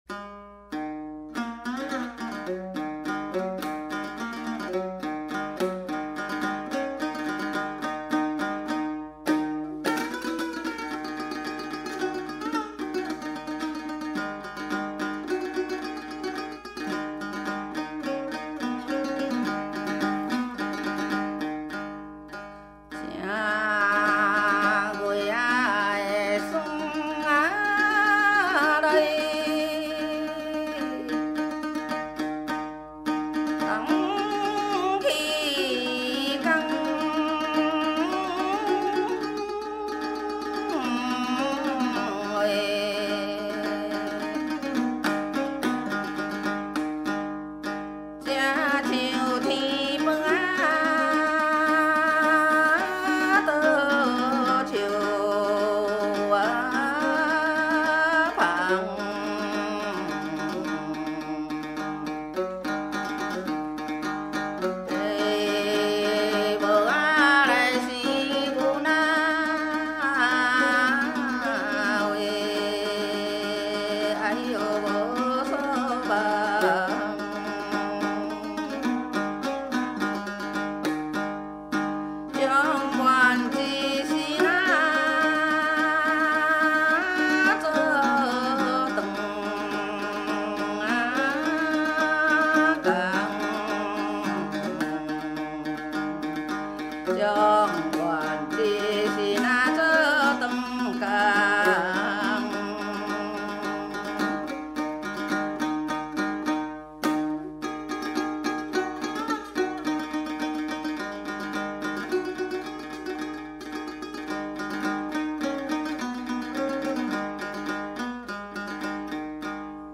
◎制作群 ： 演出：月琴,壳仔弦｜
台湾民歌
18首原汁原味的素人歌声，在粗哑中满怀真性情，那是上一代的回忆，这一代的情感，下一代的宝藏！